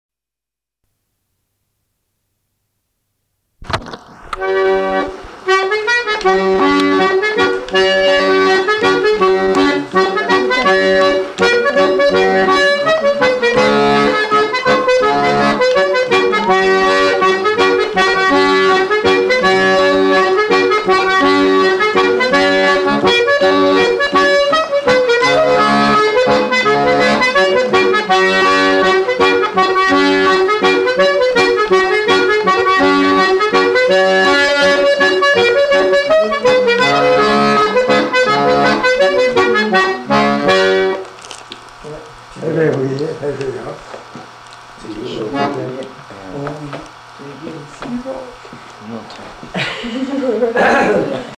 Aire culturelle : Armagnac
Lieu : Lagraulas
Genre : morceau instrumental
Instrument de musique : accordéon diatonique
Danse : scottish